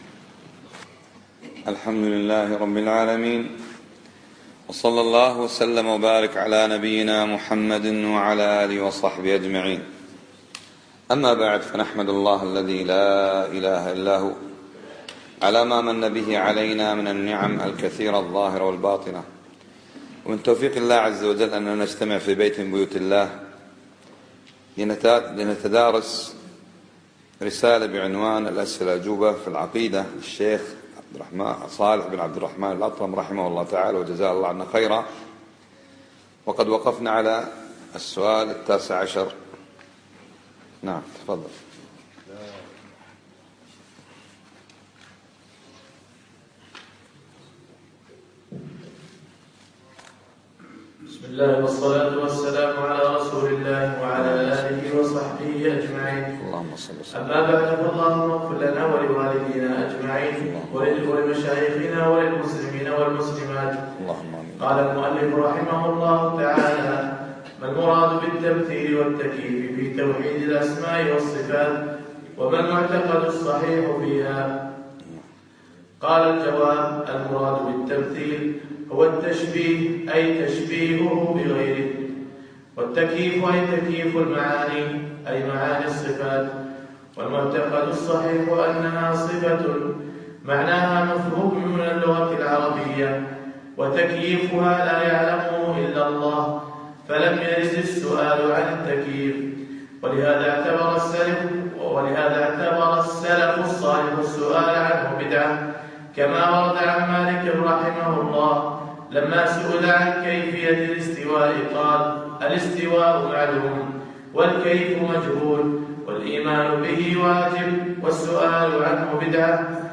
يوم الأربعاء 18 رجب 1436 الموافق 7 5 2015 بمسجد سعد سلطان السالم بمنطقة الفنطاس
الدرس السابع